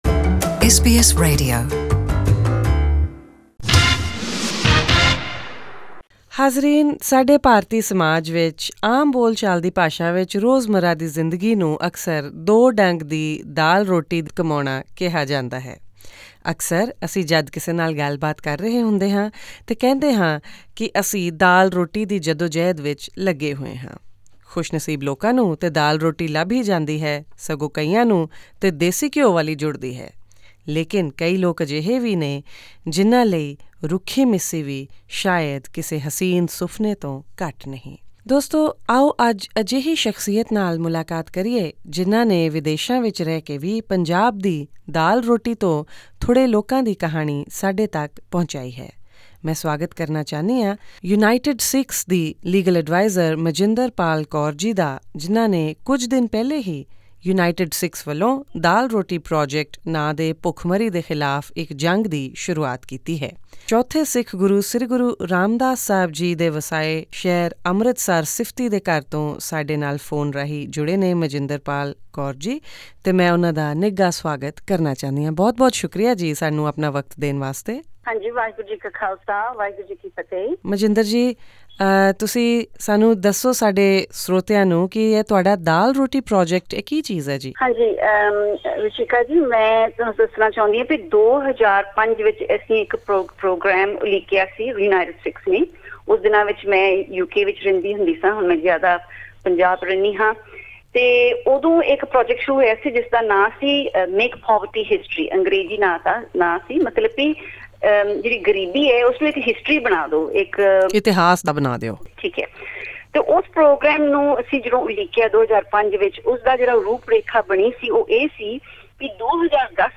Listen more about the Daal-Roti Project in this interview.